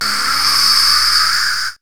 1806L SYN-FX.wav